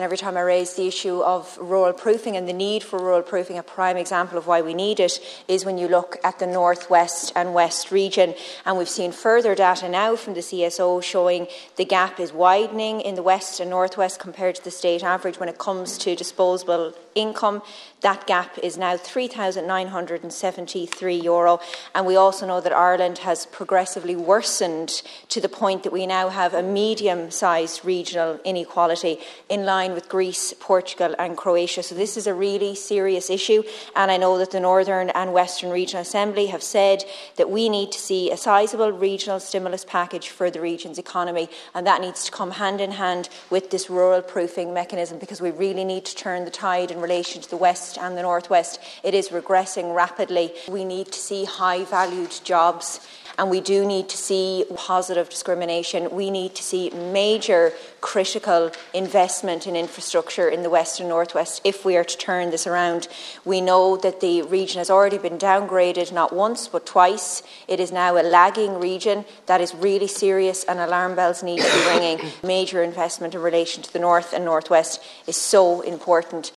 In the Dail this morning, Sinn Fein’s Rural Development spokesperson Claire Kerrane called for a significant investment package for the region.